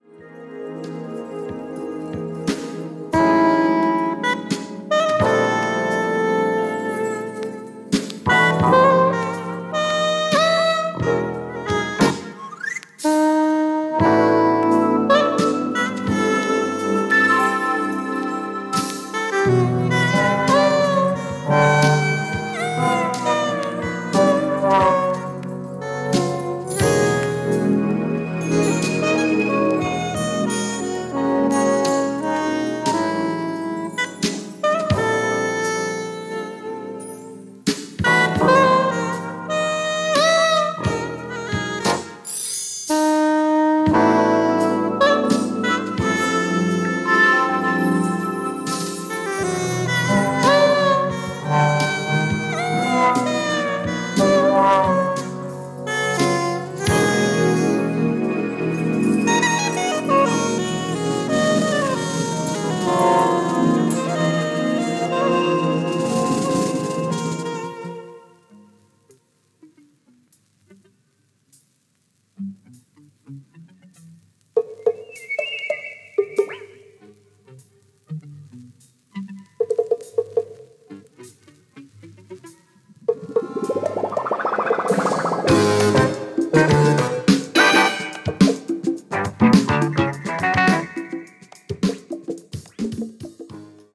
Fusion Jazz Band